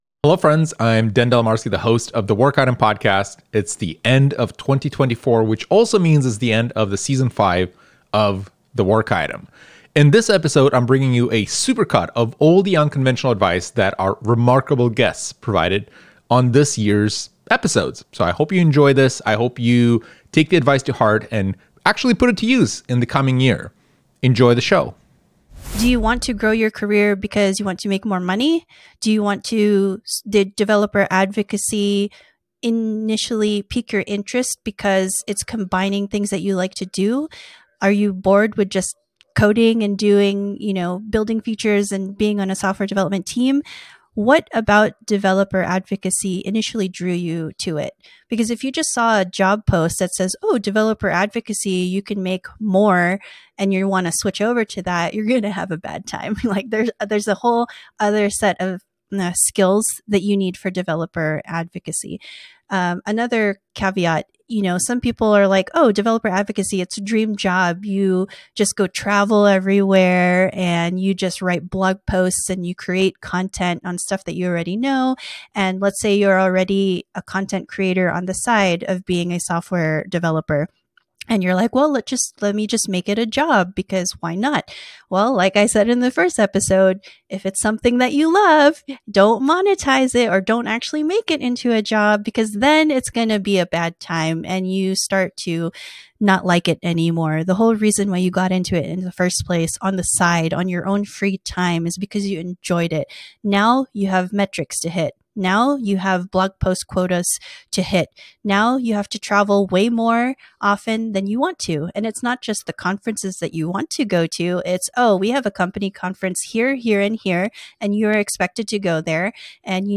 This is the last episode for 2024. To make it special, it's a supercut of all the unconventional advice from every single guest that was on the show this year